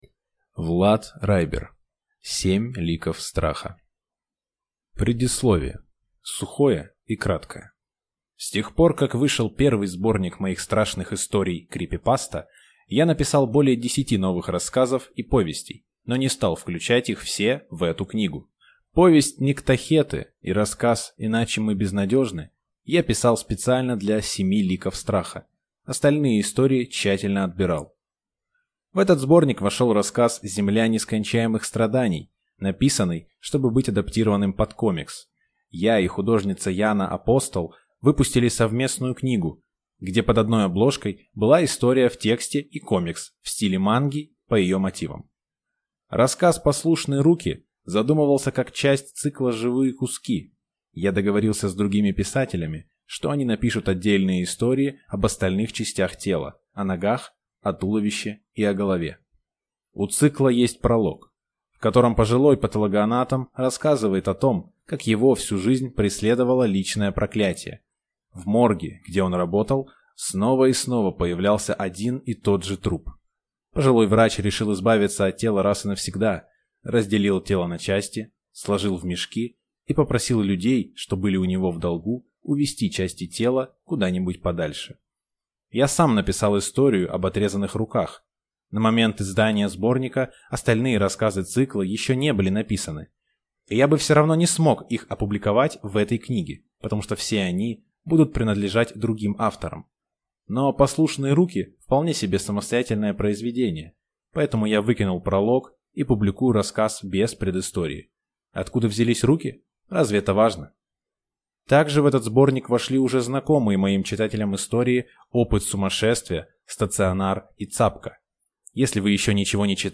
Аудиокнига Семь ликов страха | Библиотека аудиокниг